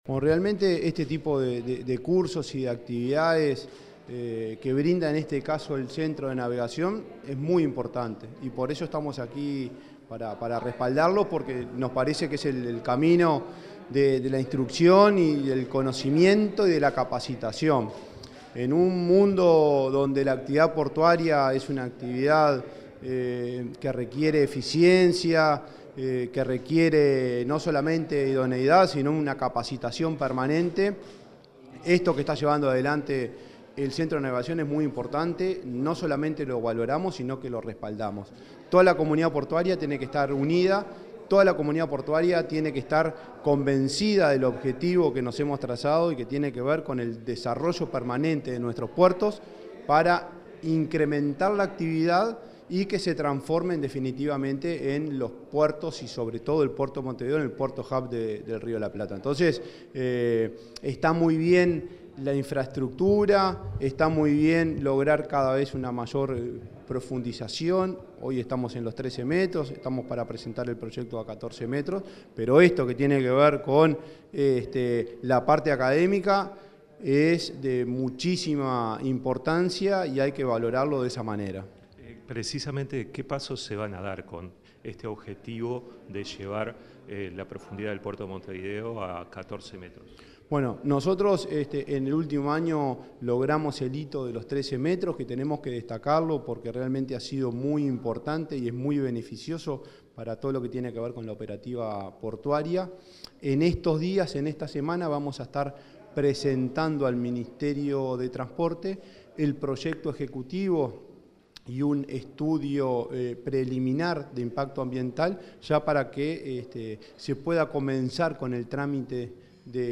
Entrevista al presidente de la ANP
El presidente de la Administración Nacional de Puertos (ANP), Juan Curbelo, dialogó con Comunicación Presidencial luego de exponer en el Centro de